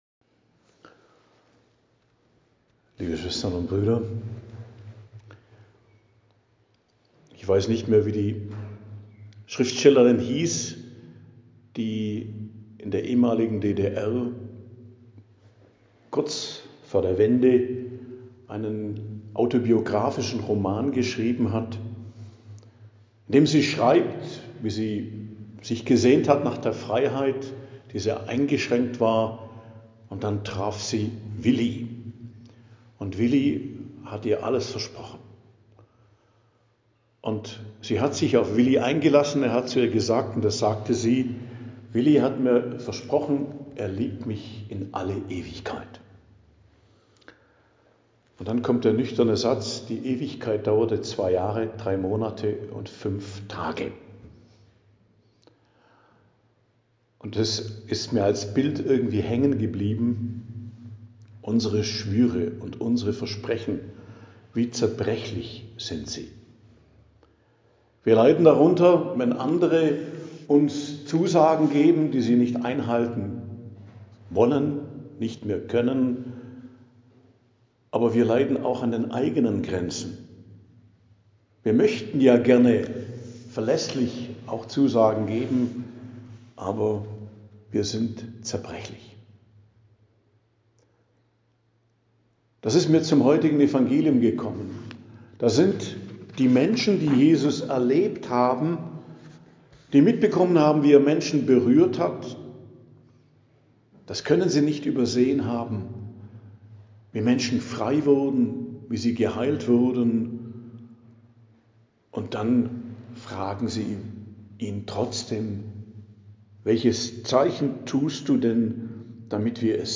Predigt am Donnerstag der 3. Osterwoche, 8.05.2025